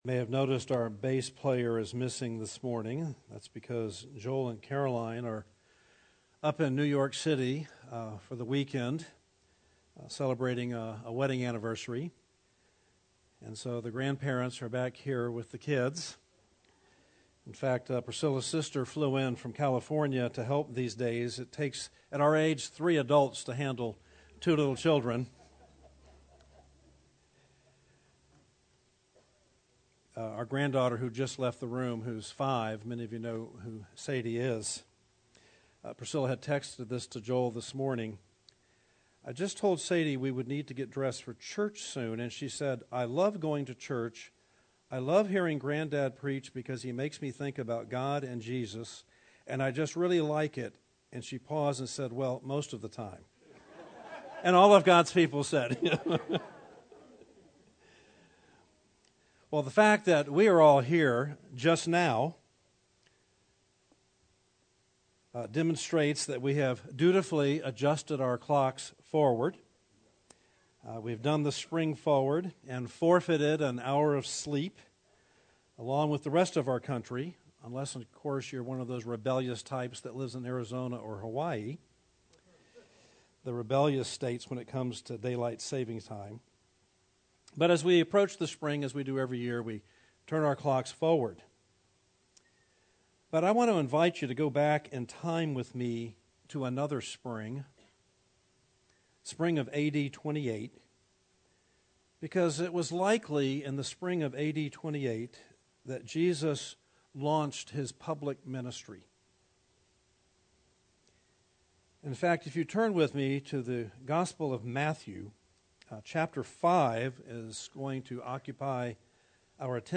Series: Jesus' Sermon on the Mount